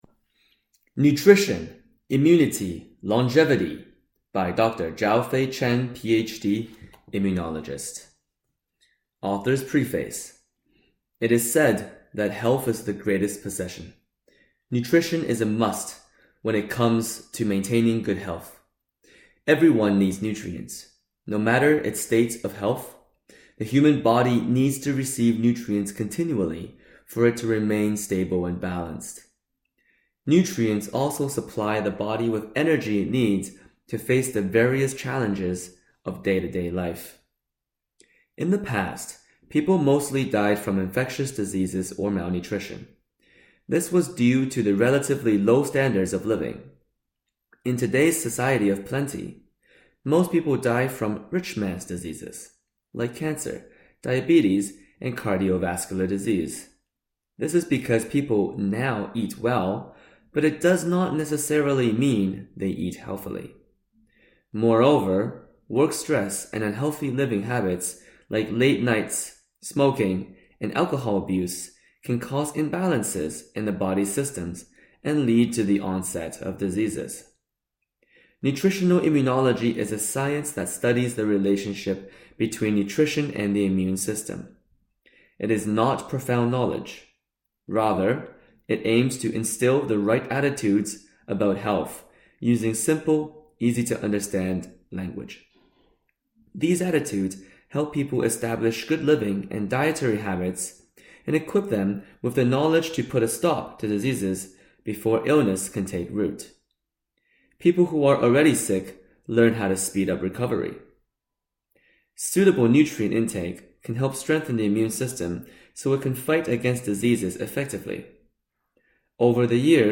Audiobook Nutritional Immunology Health knowledge pieces